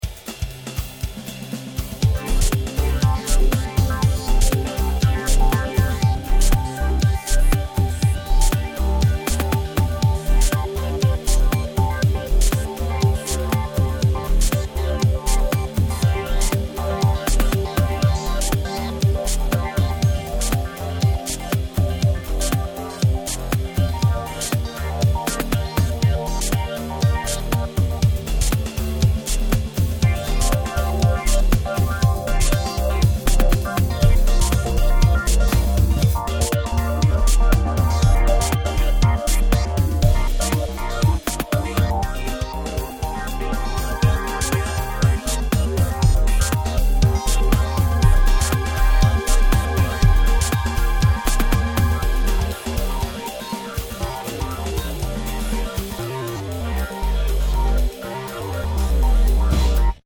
Game Soundtracks